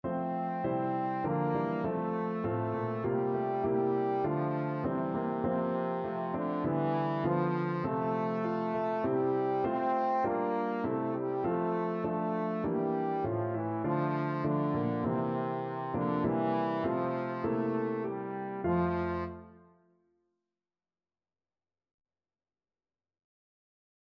Christian Christian Trombone Sheet Music The King of Love My Shepherd Is
Trombone
4/4 (View more 4/4 Music)
F major (Sounding Pitch) (View more F major Music for Trombone )